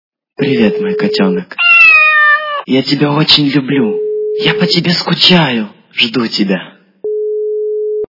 » Звуки » Люди фразы » Голос - Привет мой котeнок, я тебя очень люблю
При прослушивании Голос - Привет мой котeнок, я тебя очень люблю качество понижено и присутствуют гудки.